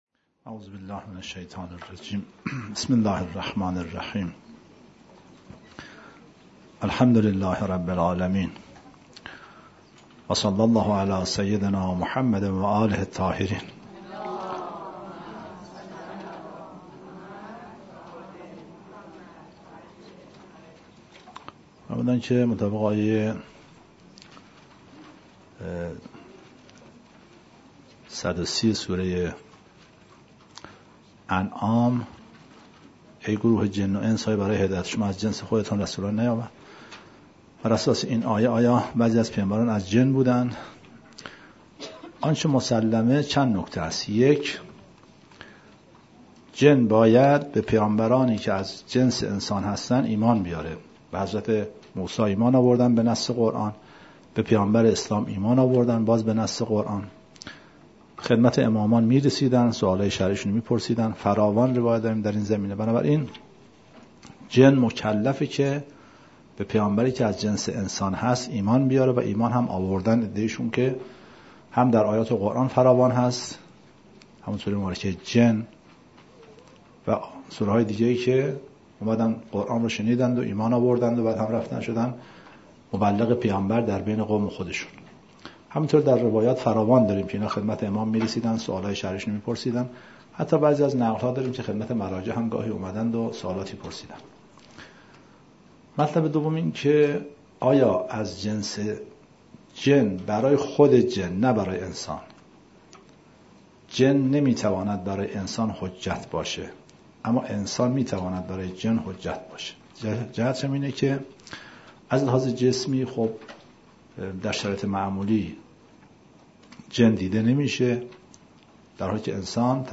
274 - تلاوت قرآن کریم